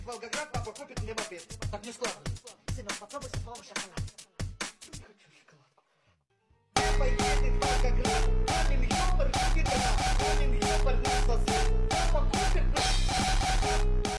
papa edet v volgograd Meme Sound Effect
Category: Meme Soundboard